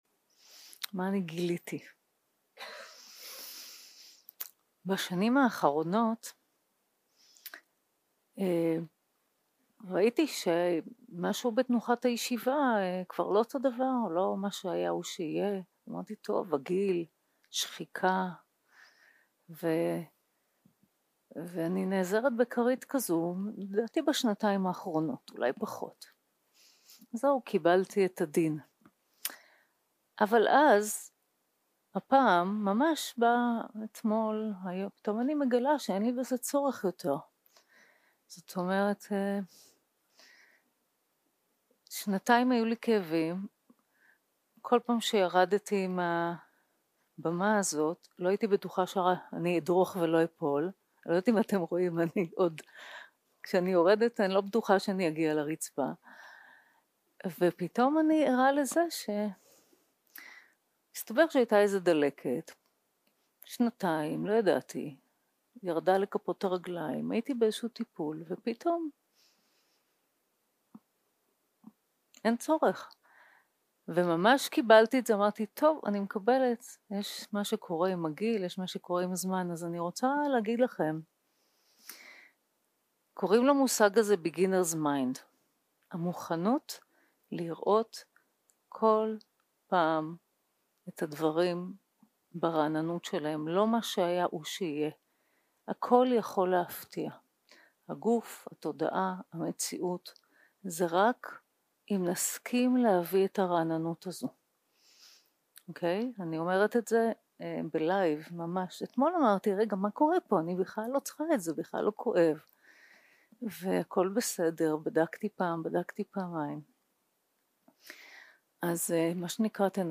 יום 5 - הקלטה 10 - בוקר - הנחיות למדיטציה - מטא - הדמות המיטיבה 0:00 0:00 סוג ההקלטה: Dharma type: Guided meditation שפת ההקלטה: Dharma talk language: Hebrew